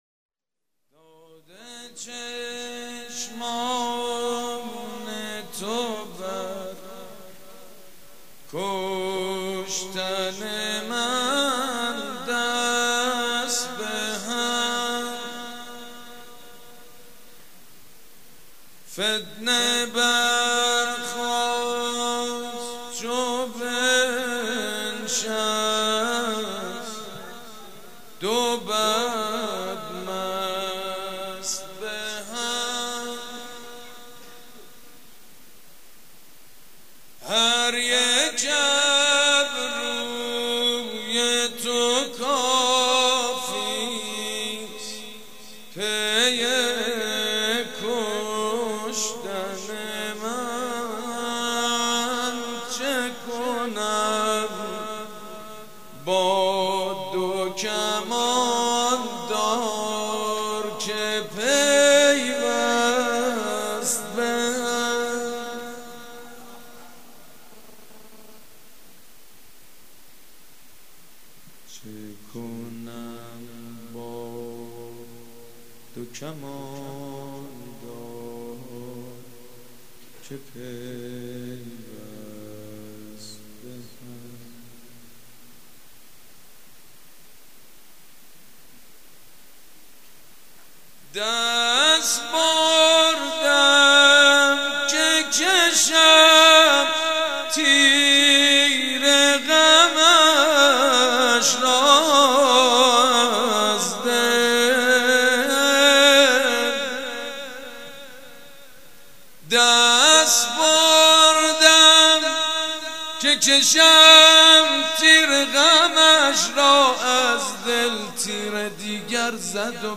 شعر خوانی
مداح
ولادت حضرت محمد (ص) و امام صادق (ع)